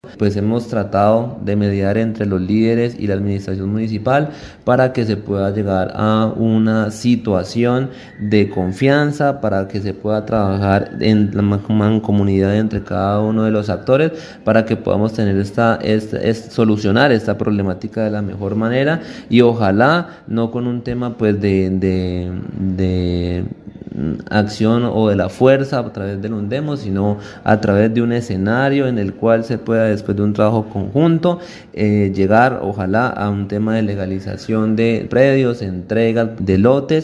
Camilo Araujo, personero del municipio de San Vicente del Caguan, explicó que la caracterización se realiza en los asentamientos urbanos denominados Cataleya 1 y 2, Yarumales y la Nueva Esperanza, donde se estima que cada uno de estos cuenta con 1500 familias.
PERSONERO_CAMILO_ARAUJO_CENSO_-_copia.mp3